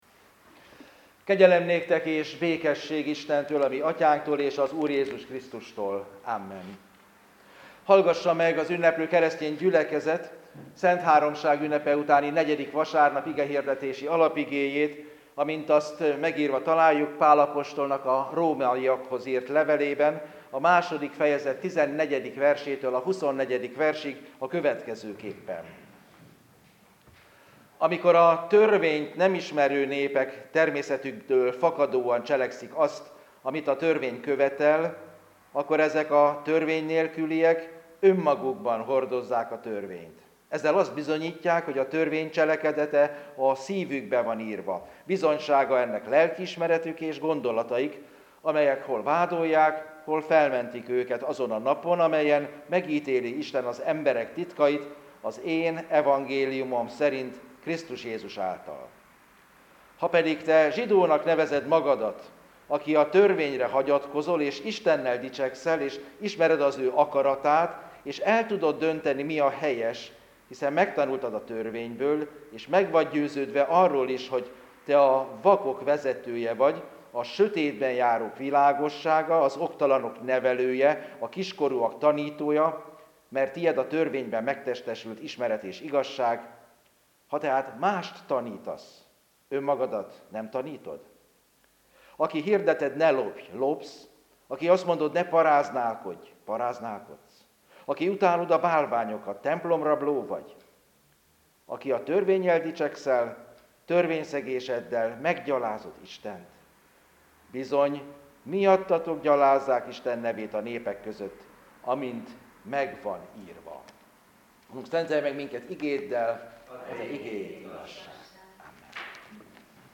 Igehirdetés Szentháromság ünnepe utáni 4. vasárnap Róm 2,14-24 alapján.